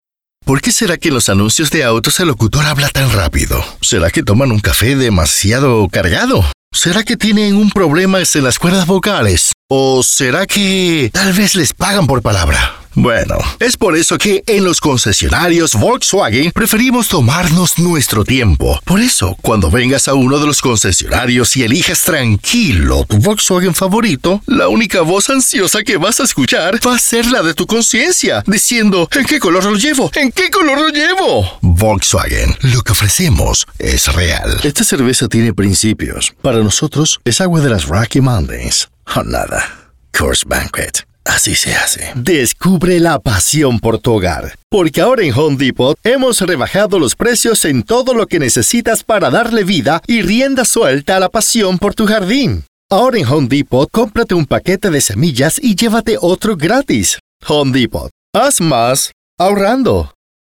A warm and deep native Latin American Spanish voice ideal for narrations, sexy and fun for commercials, smooth and professional for presentations.
Sprechprobe: Werbung (Muttersprache):